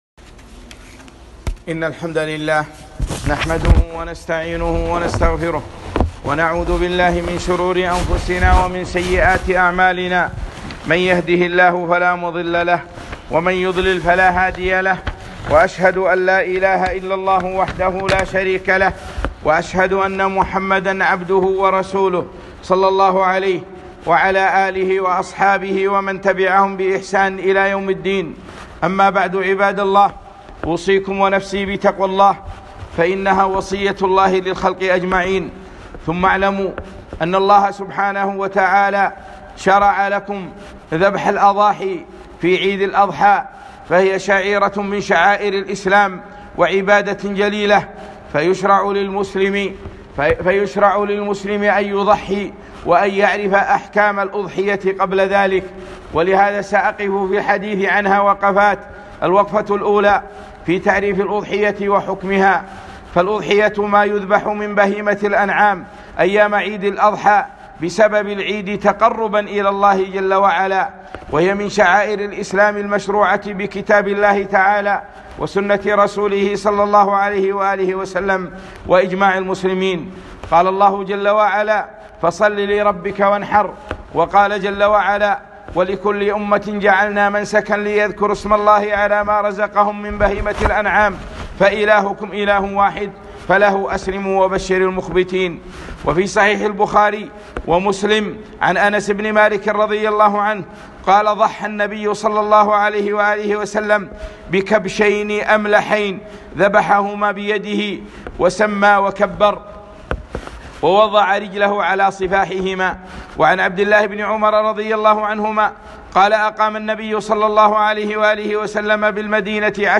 خطبة - أحكام الأضحية 3 ذو الحجة 1441 هــ